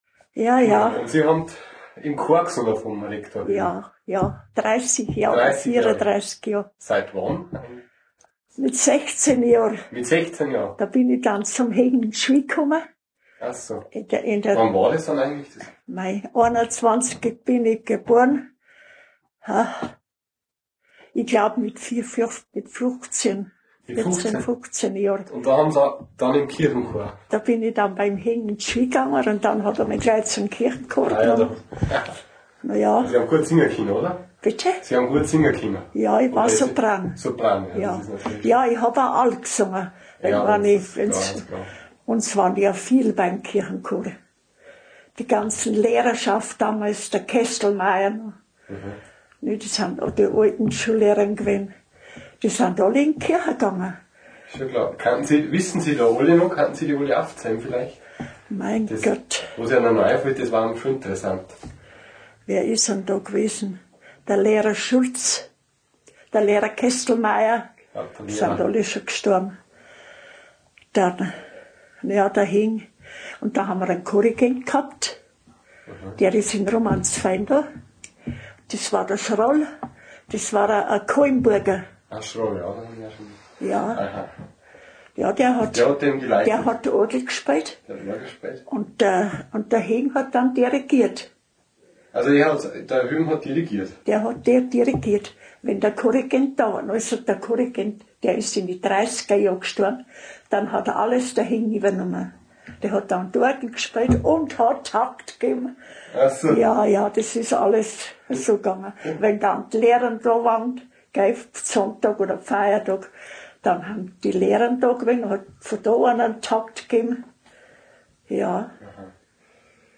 interview.m4a